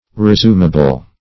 Search Result for " resumable" : The Collaborative International Dictionary of English v.0.48: Resumable \Re*sum"a*ble\, a. Capable of, or admitting of, being resumed.
resumable.mp3